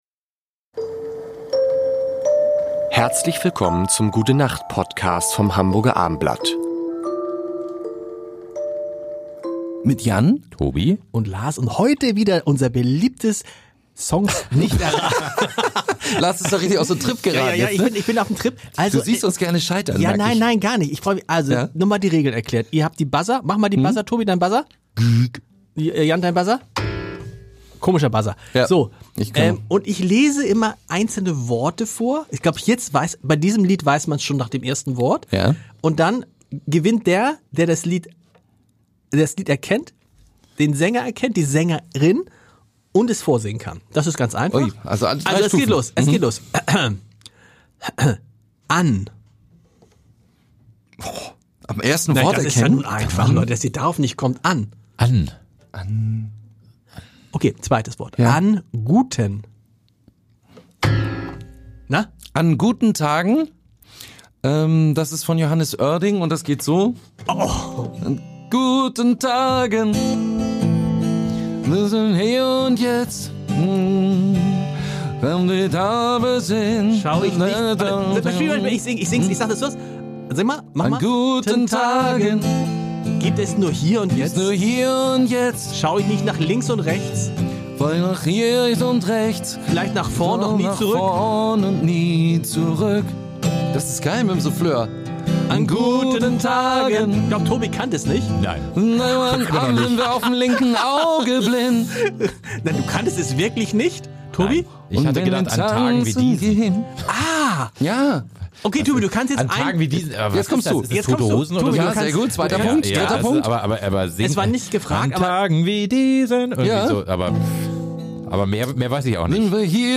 Jetzt jede Woche: Songtexte raten und singen